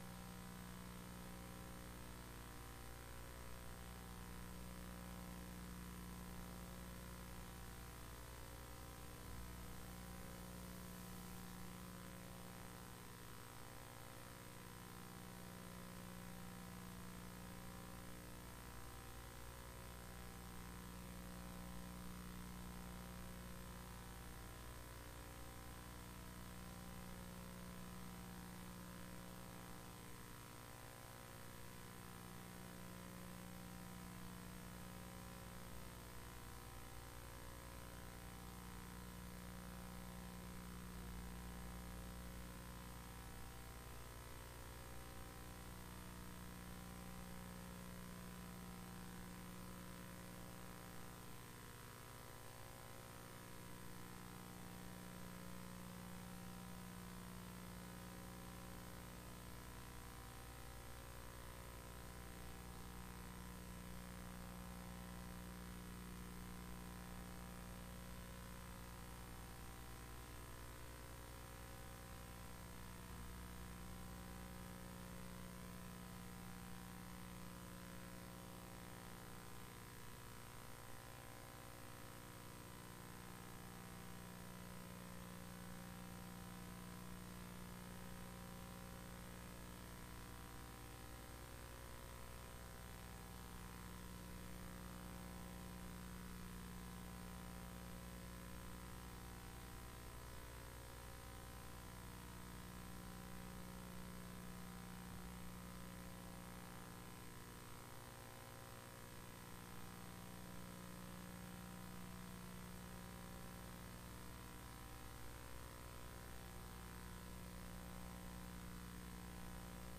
Isaiah 26:3 Service Type: Sunday AM God's Perfect Peace Can Be Yours Isaiah 26:3 I. God's Peace Explained II.